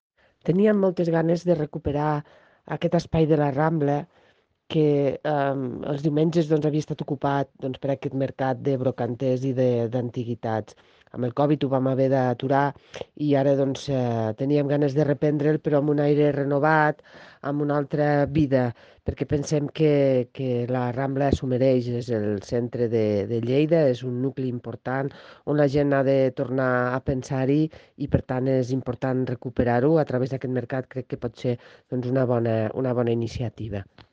tall-de-veu-de-la-regidora-marta-gispert-sobre-el-treball-que-es-vol-fer-amb-el-renovat-mercat-de-la-rambla